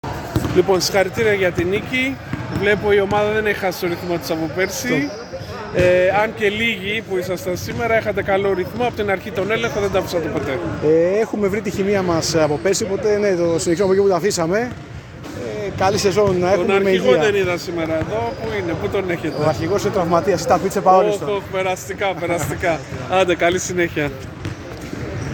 GAMES INTERVIEWS: